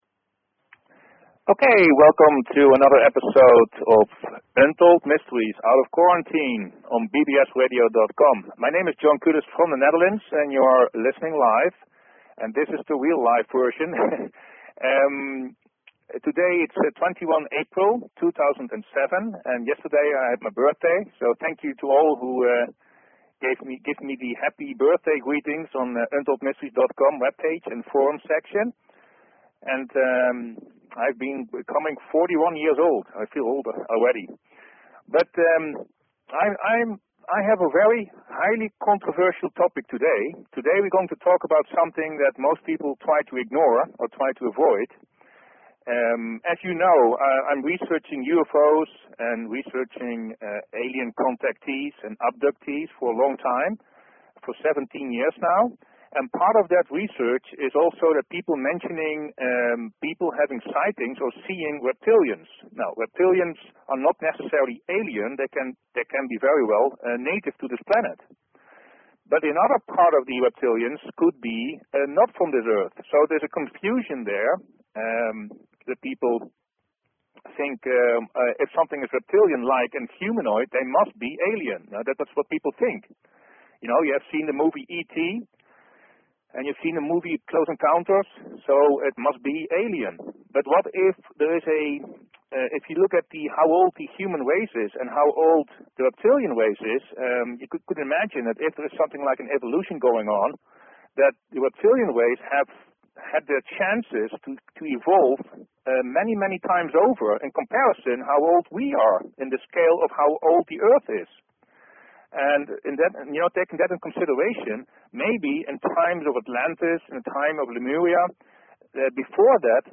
Talk Show Episode, Audio Podcast, UntoldMysteries and Courtesy of BBS Radio on , show guests , about , categorized as